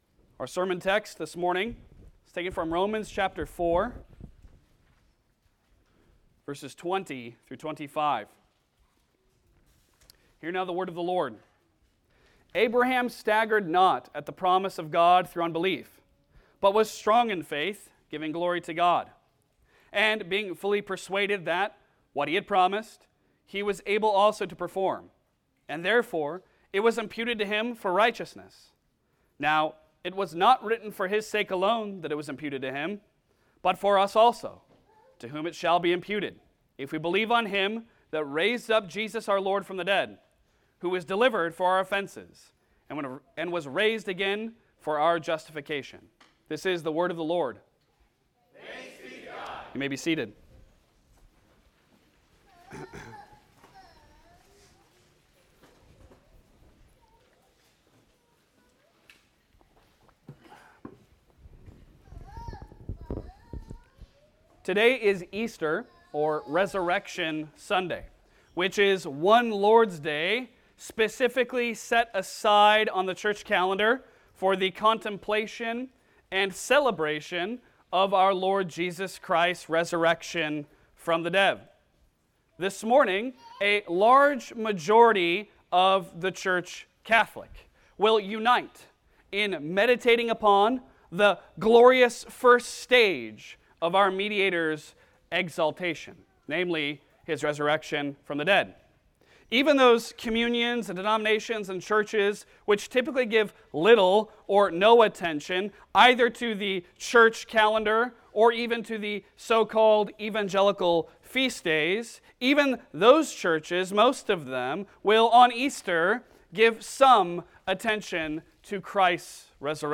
Passage: Romans 4:20-25 Service Type: Sunday Sermon